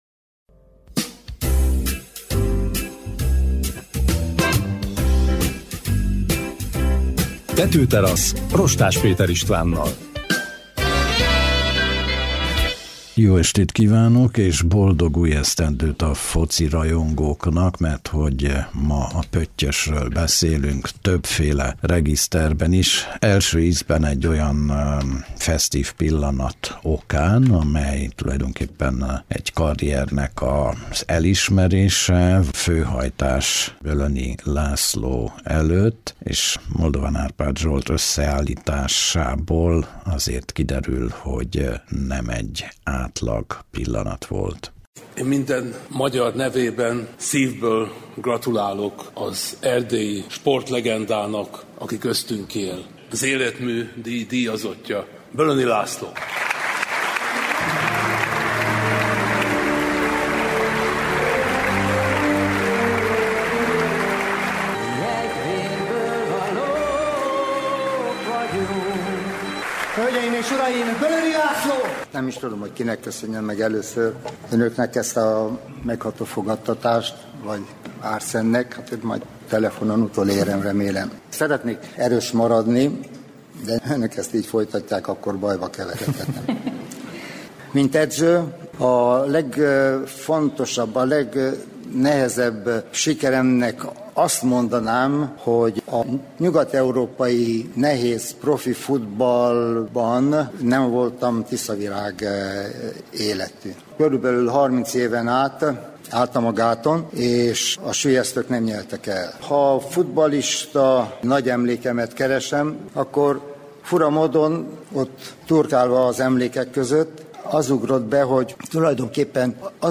Hétvégén indul újra a hazai futball-bajnokság, ennek ürügyén a minusz fokok ellenére pályára állunk. Sportkommentátoraink a Tetőteraszon.